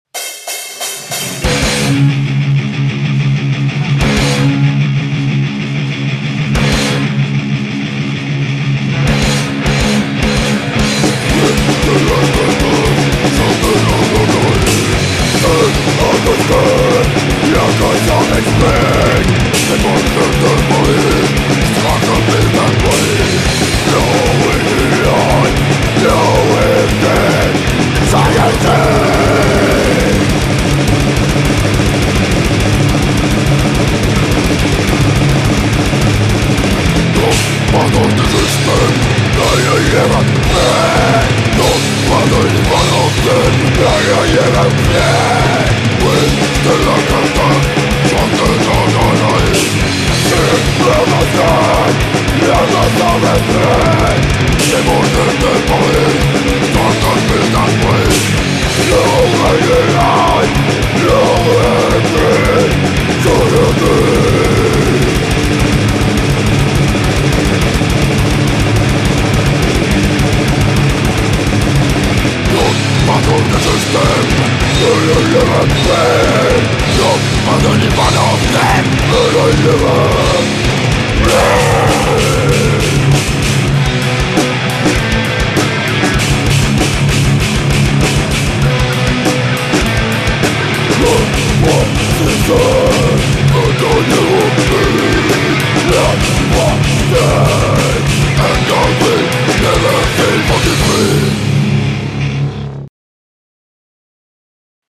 ULEÅBORG HARDCORE
10 piisiä äänitetty Helgate studiolla 11.9.2005.